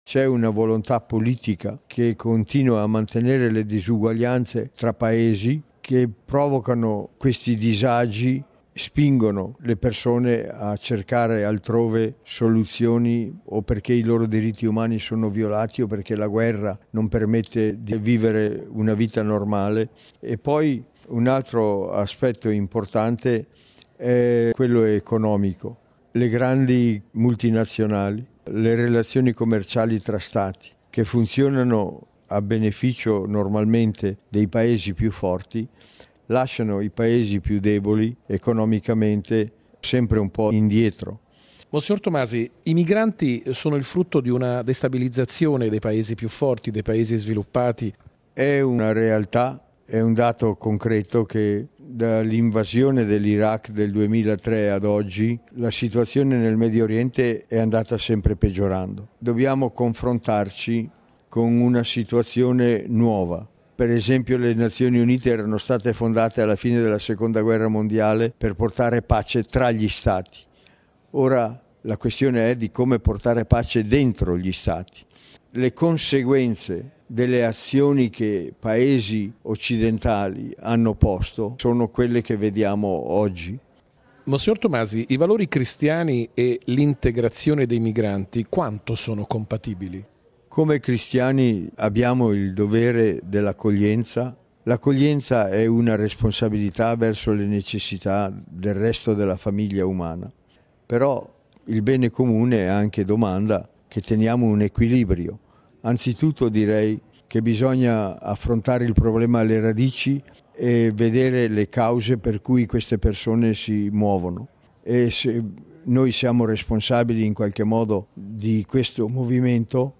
Al Meeting di Rimini è intervenuto oggi mons. Silvano Maria Tomasi. L’osservatore permanente della Santa Sede presso l’Ufficio Onu di Ginevra, ha parlato delle attuali crisi mondiali a partire dal dramma delle migrazioni.